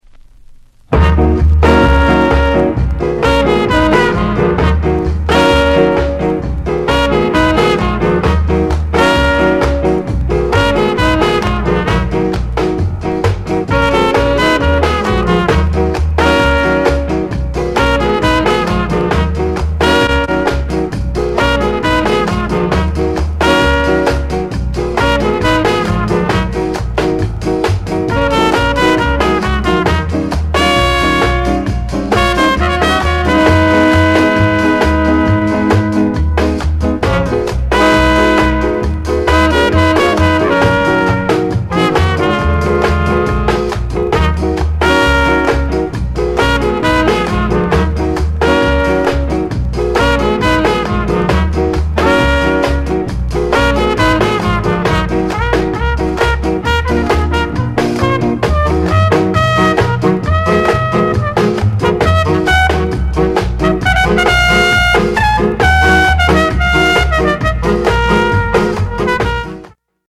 KILLER SKA INST